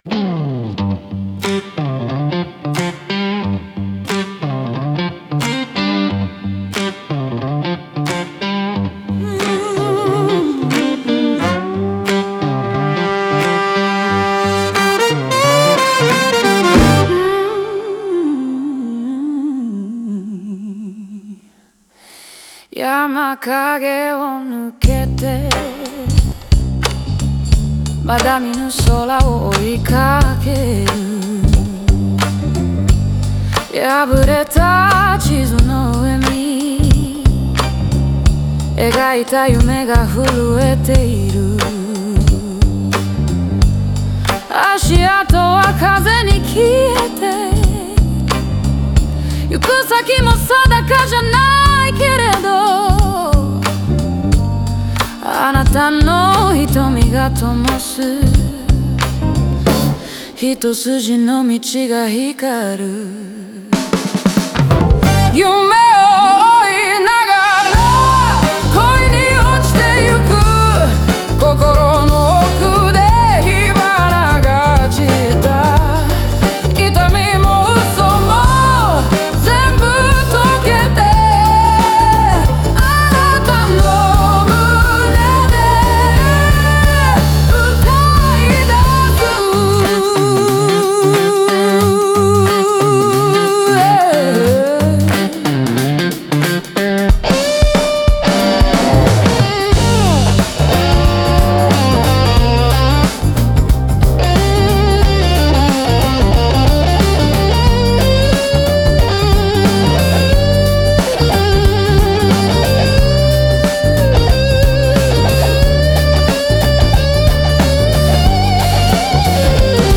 オリジナル曲♪
ブルースやソウルの熱量を帯びたリズムが、内なる情熱と解放の瞬間を強調しています。